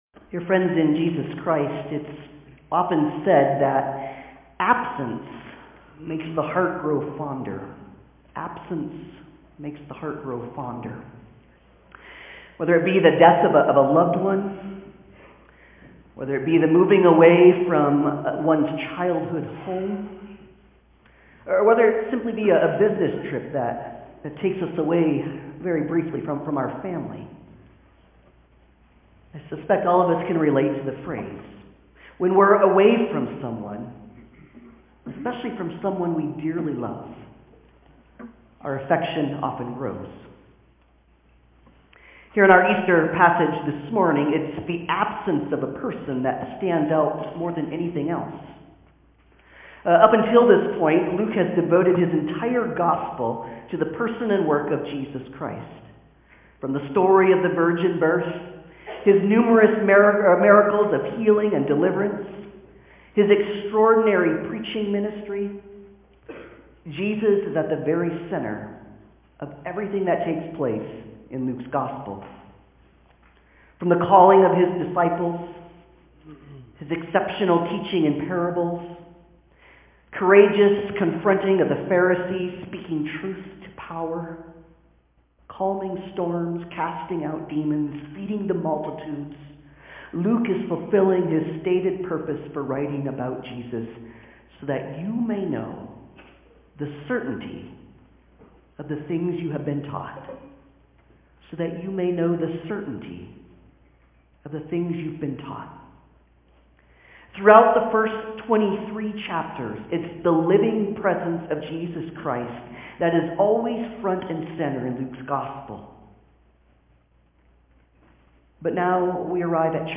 Luke 24:1-12 Service Type: Sunday Service « The Parable of the Tenants Transformed and Renewed!